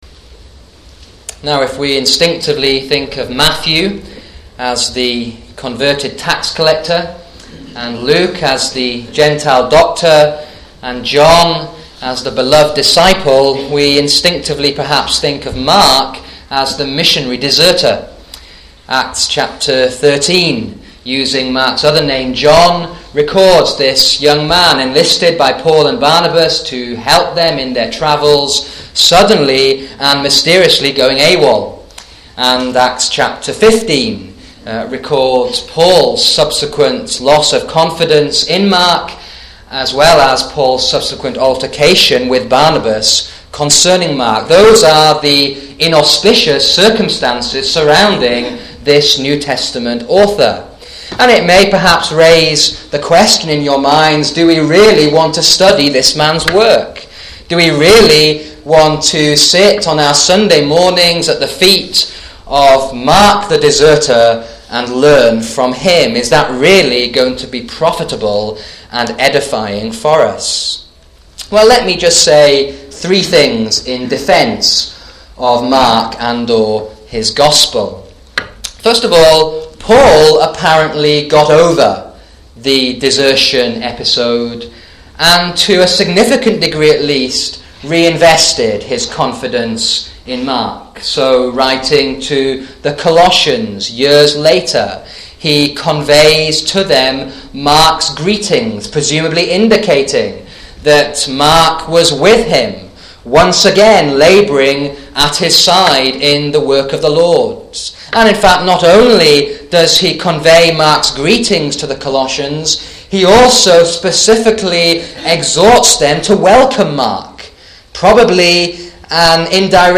Poor sound quality - but it's all there.